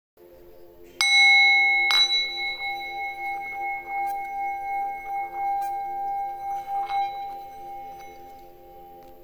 Bol tibétain - La boutique de Miss Larimar
Bol tibétain traditionnel en 7 métaux.
Note : Sol.
mini-bol.mp3